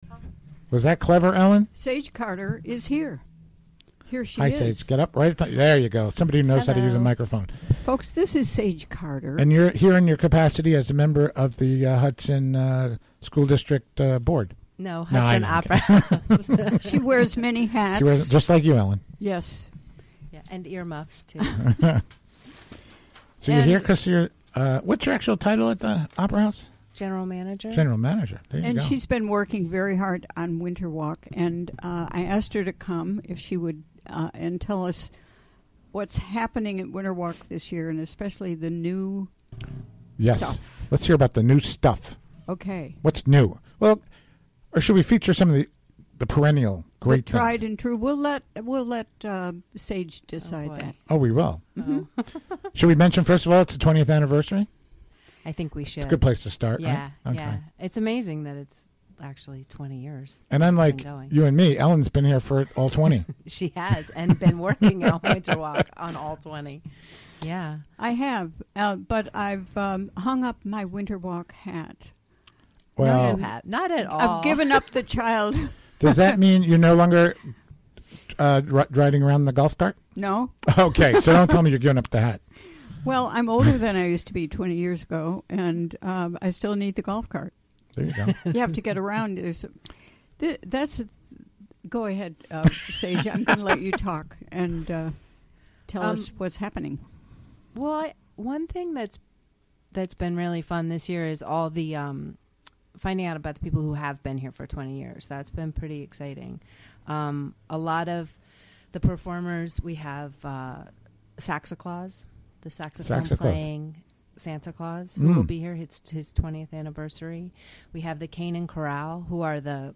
Recorded in WGXC's Hudson Studio and broadcast on the WGXC Afternoon Show Thursday, December 1, 2016.